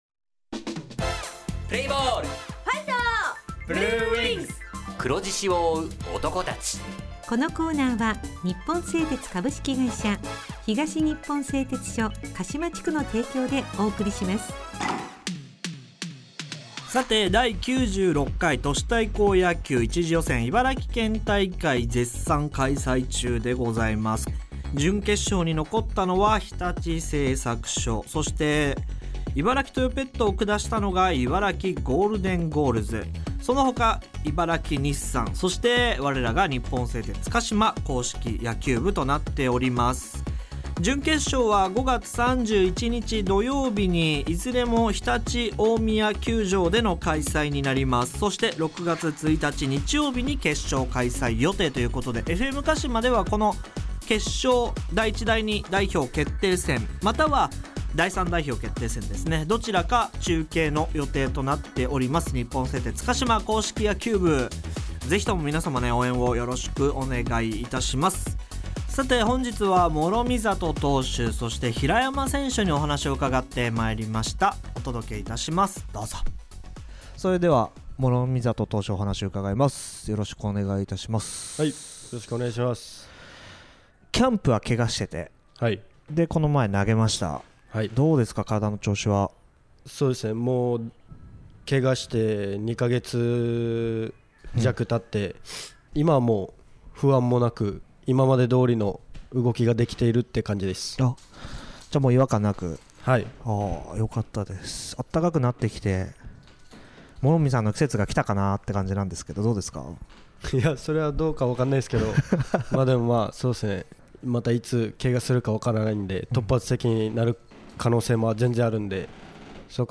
地元ＦＭ放送局「エフエムかしま」にて鹿島硬式野球部の番組放送しています。
《選手インタビュー》